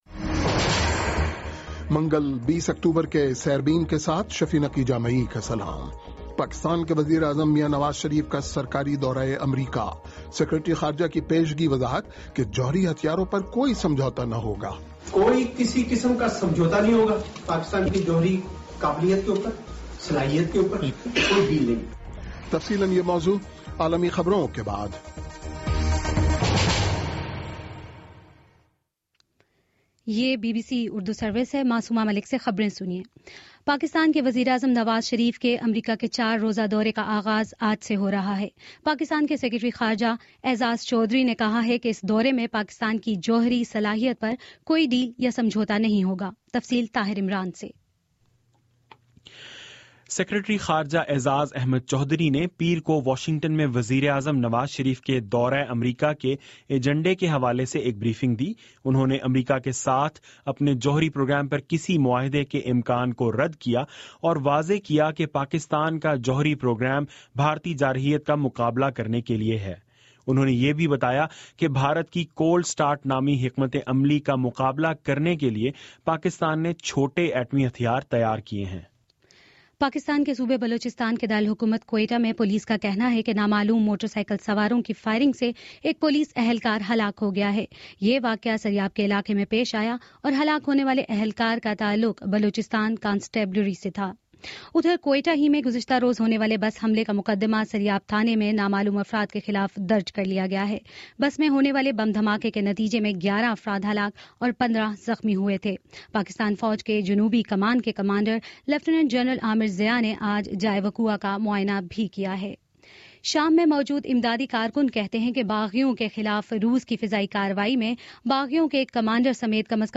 منگل 20 اکتوبر کا سیربین ریڈیو پروگرام